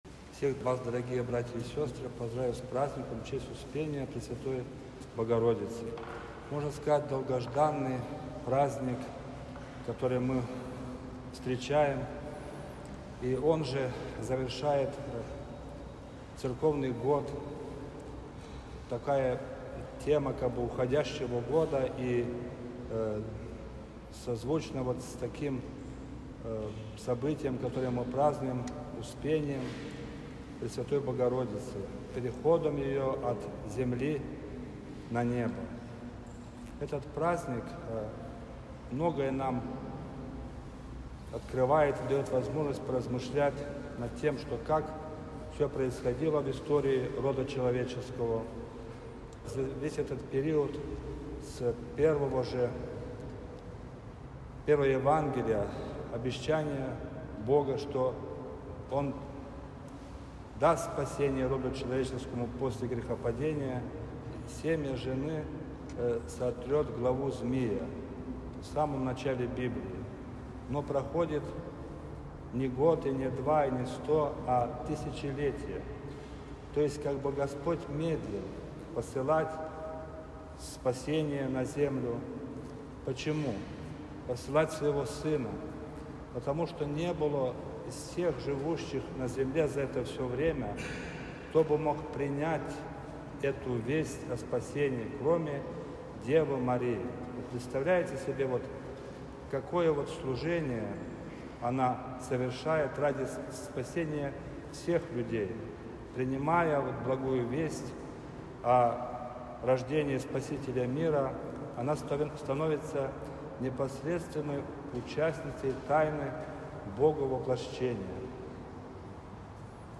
Проповедь
Всенощное-бдение-2.mp3